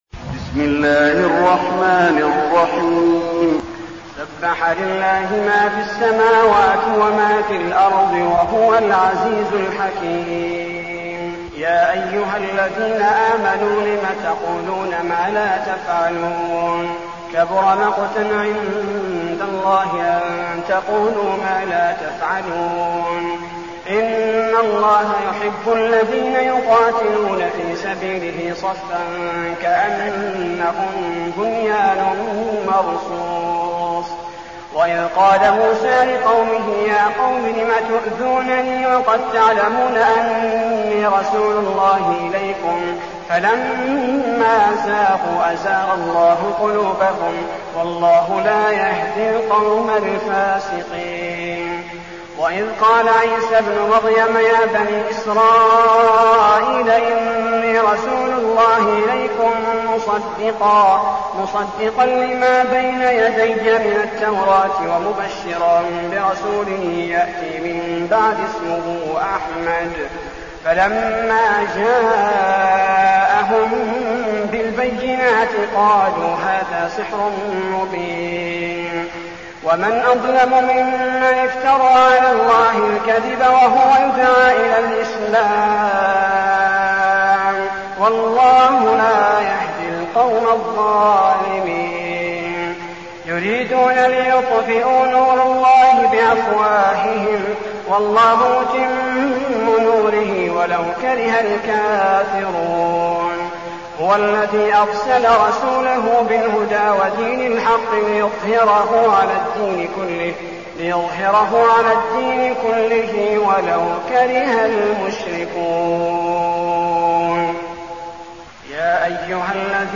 المكان: المسجد النبوي الصف